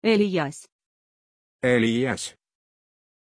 Prononciation de Eliyas
pronunciation-eliyas-ru.mp3